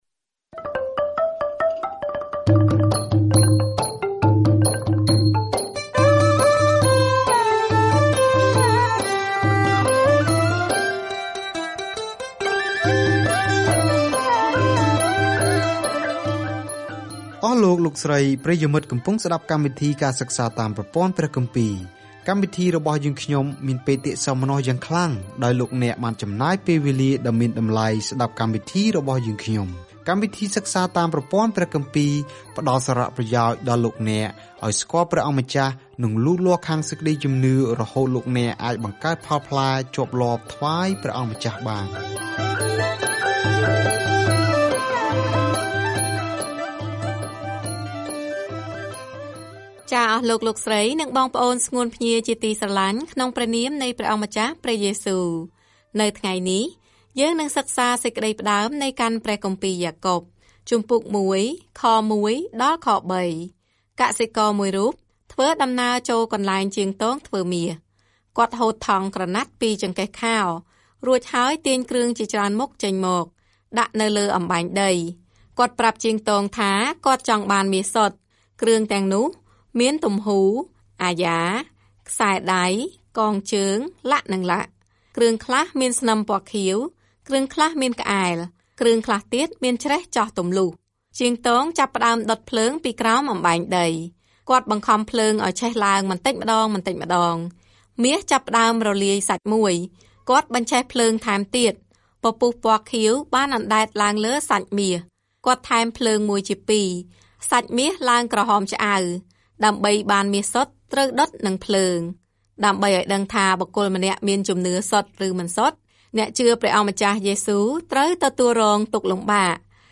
ការធ្វើដំណើរជារៀងរាល់ថ្ងៃតាមរយៈយ៉ាកុប នៅពេលអ្នកស្តាប់ការសិក្សាជាសំឡេង ហើយអានខគម្ពីរដែលជ្រើសរើសពីព្រះបន្ទូលរបស់ព្រះ។